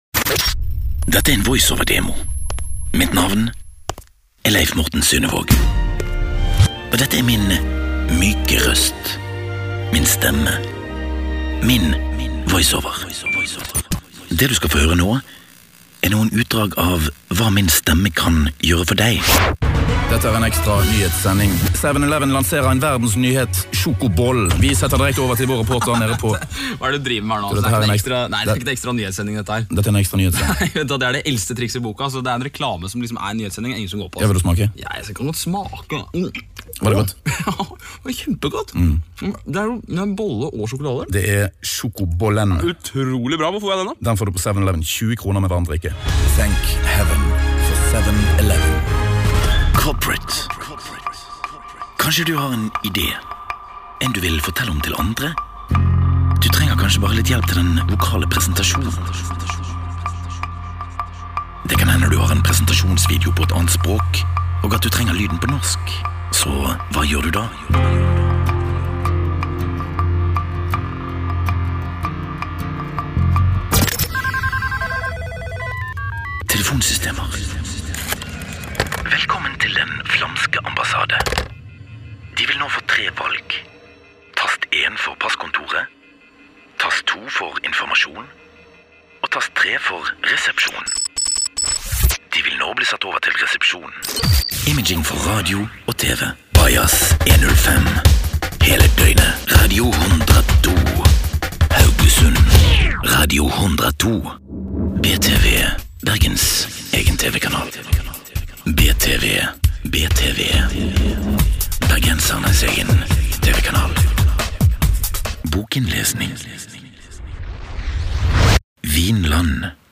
Trustworthy, Informative, Versatile - Deep baryton
Sprechprobe: Werbung (Muttersprache):